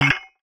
UIClick_Metal Hits Muffled 03.wav